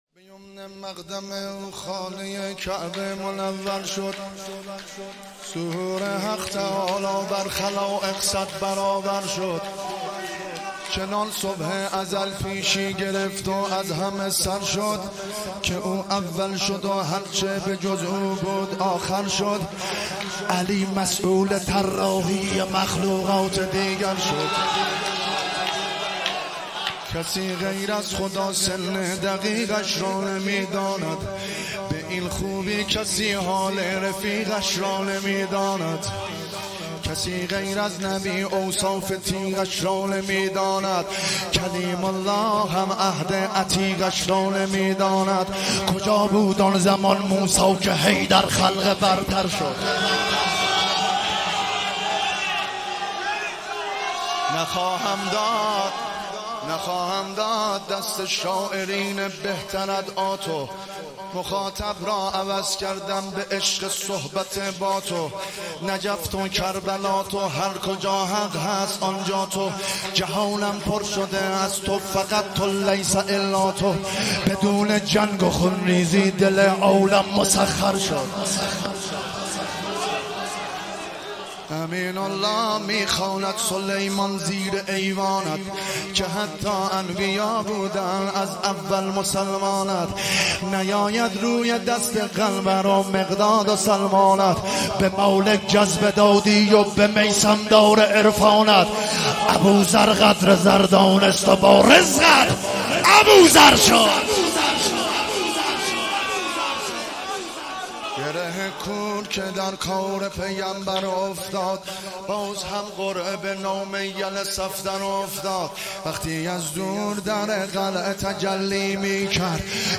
صابر خراسانی ولادت حضرت عباس (ع) هیئت مکتب العباس(ع) خمینی شهر پلان3